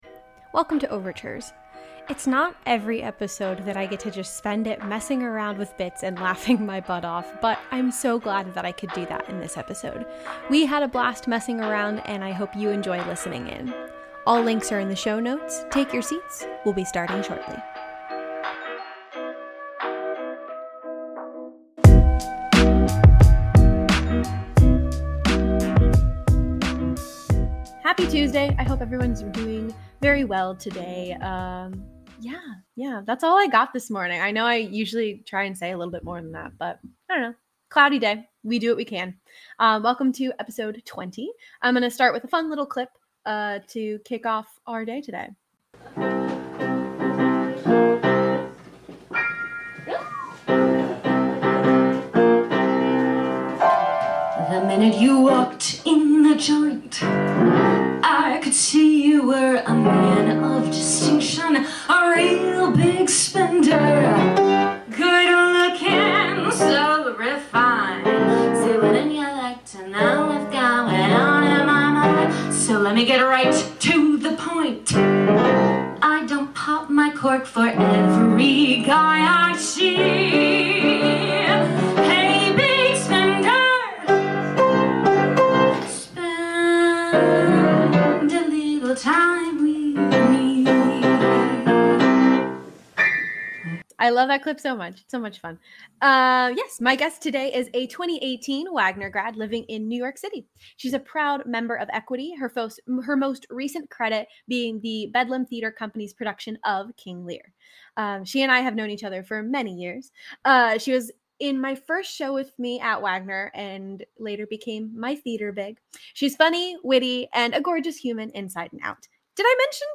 Overtures